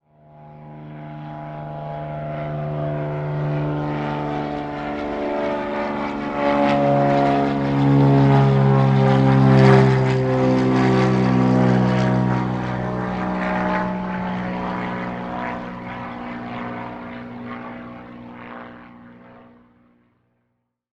دانلود آهنگ سوت هواپیما 3 از افکت صوتی حمل و نقل
دانلود صدای سوت هواپیما 3 از ساعد نیوز با لینک مستقیم و کیفیت بالا
جلوه های صوتی